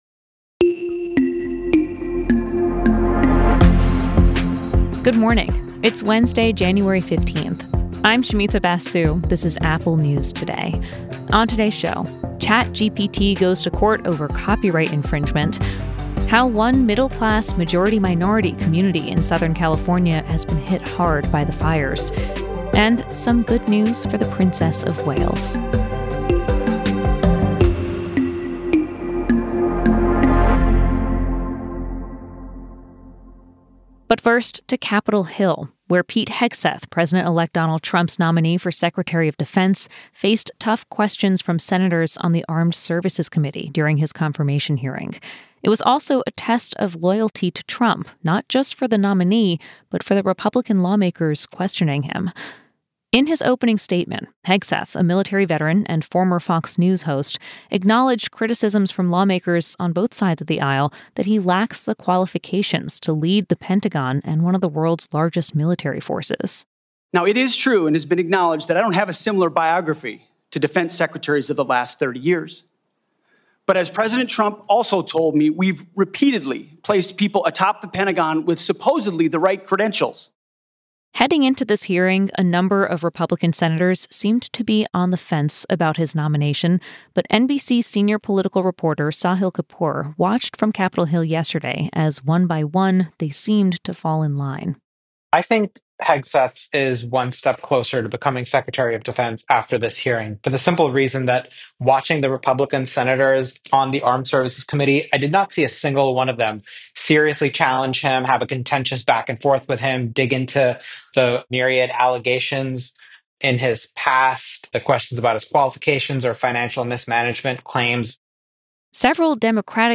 … continue reading 1189 episodios # Daily News # News # Apple News Editors